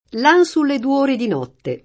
l# n Sulle du 1re di n0tte] (Firenzuola) — come s. m., pl. i due (pop. i dui), «i numeri 2» — ant. duo [d2o], solo maschile